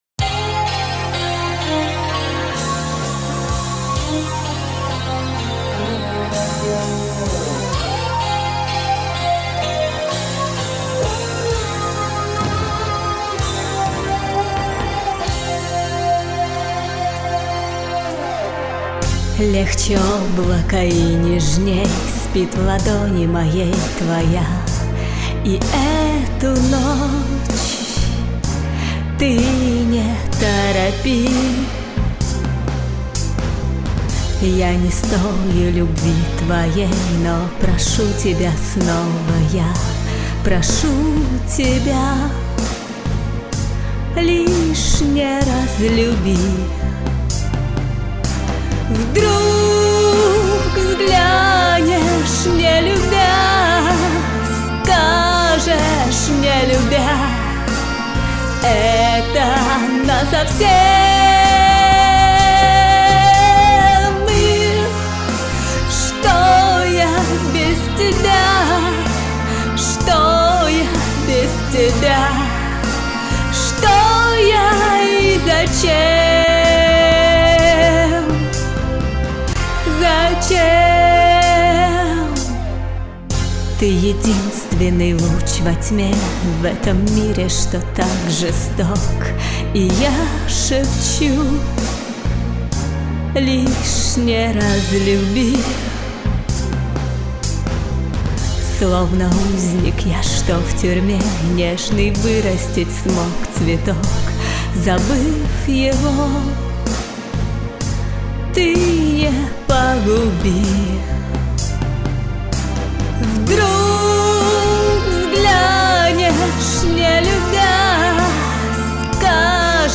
И голос хороший.